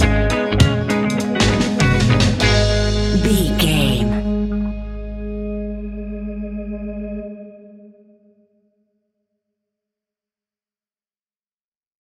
A nice bouncy and upbeat piece of Reggae music.
Aeolian/Minor
G#
Slow
reggae instrumentals
laid back
chilled
off beat
drums
skank guitar
hammond organ
transistor guitar
percussion
horns